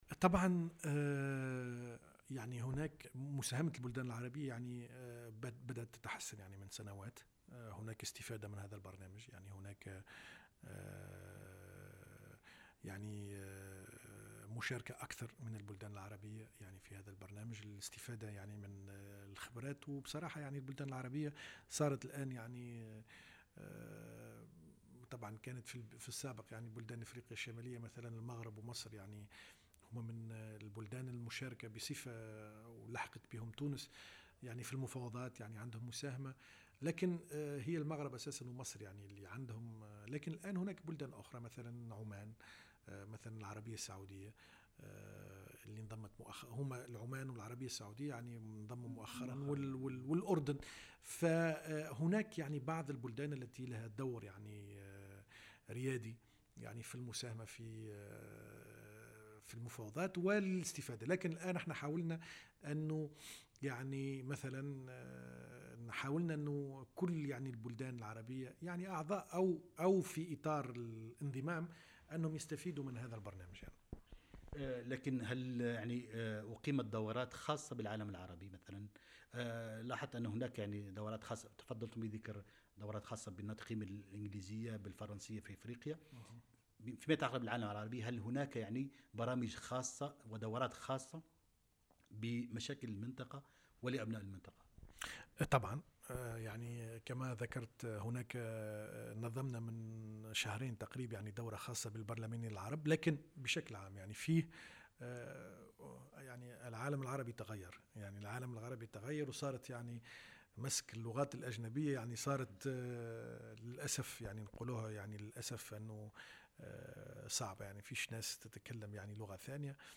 الدكتور حكيم بن حمودة، مدير معهد التكوين والتعاون التقني بمنظمة التجارة العالمية يشرح استفادة العالم العربي من خدمات المعهد